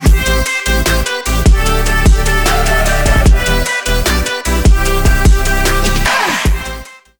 Ищу голосовой сэмпл "Хэй!"
Помогите найти мужский хоровой сэмпл Хэй. Обычно такой выкрик используется в восточной музыке.